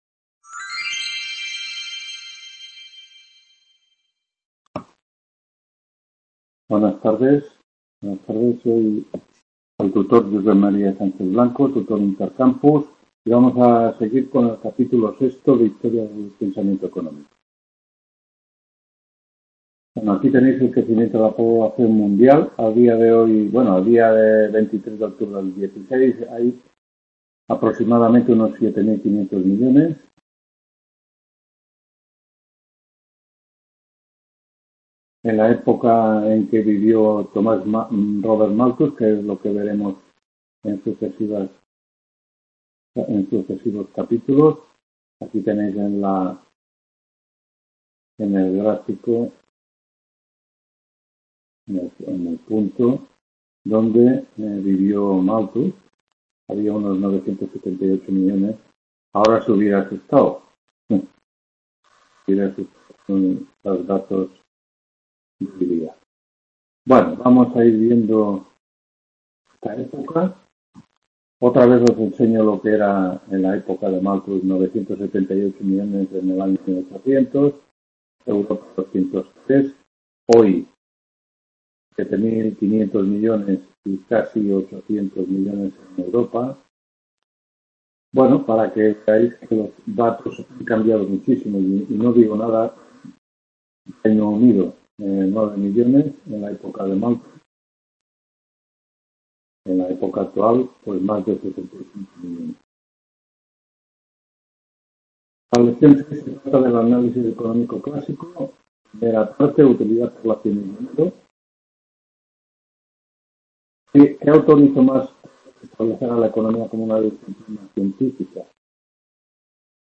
3ª TUTORÍA HISTORIA DEL PENSAMIENTO ECONÓMICO 24-X-18…